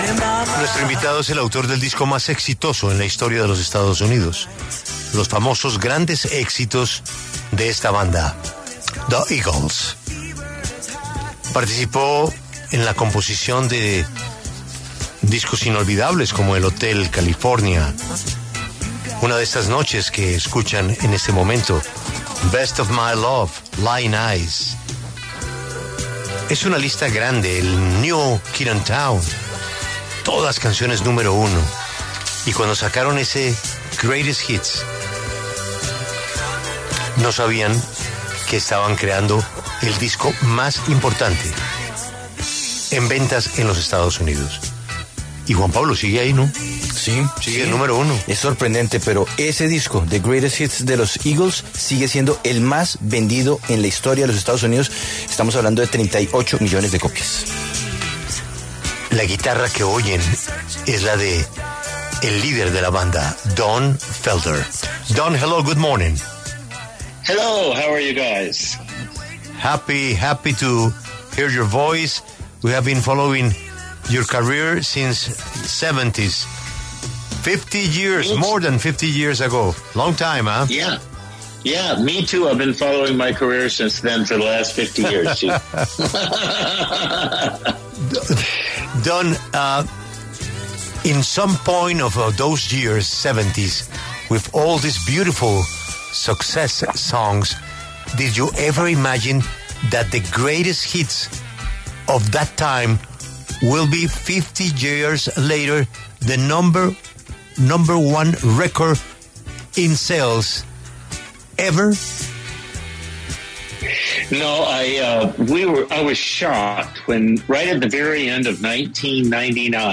Don Felder, músico estadounidense que fue guitarrista principal de la banda de rock ‘Eagles’, habla en La W de su trayectoria artística y sus nuevos lanzamientos.